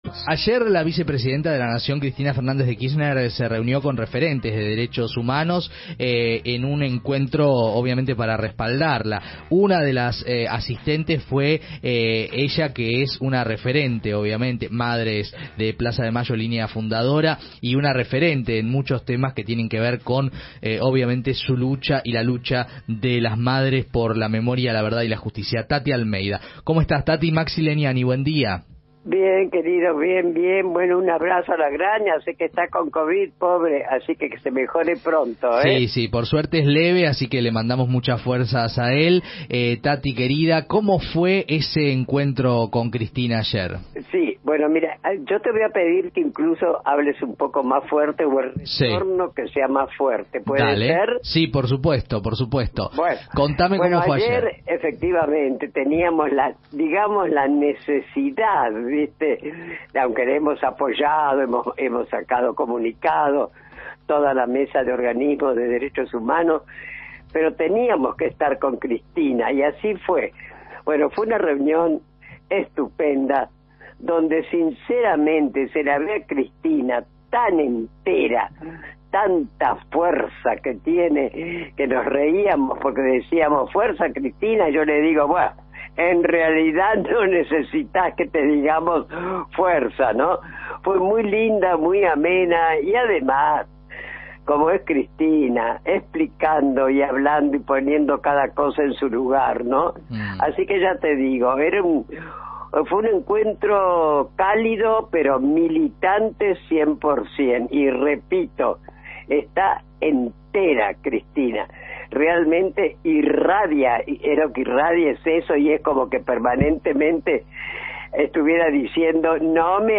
Entrevista a Taty Almeida